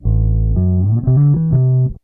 BASS 16.wav